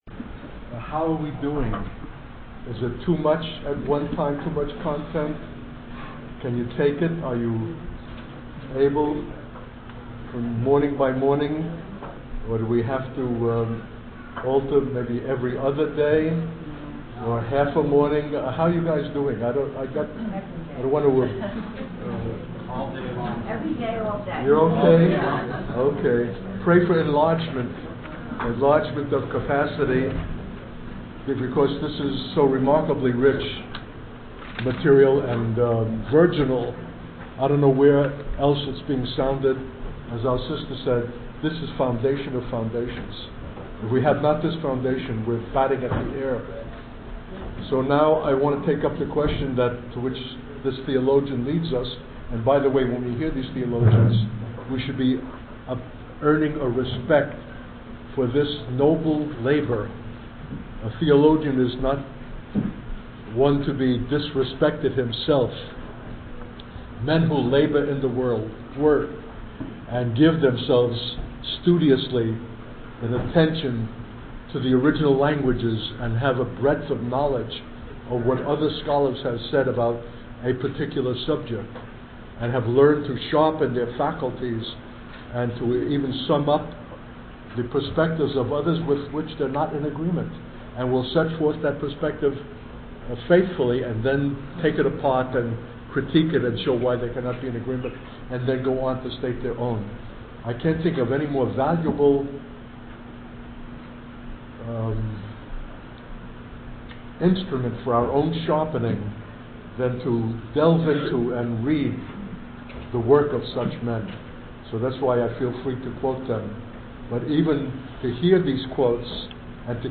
In this sermon, the speaker emphasizes the importance of the church taking responsibility for the well-being of their fellow human beings, particularly those who are marginalized and mistreated. He urges the church to assert itself and confront those in power who neglect and dehumanize others.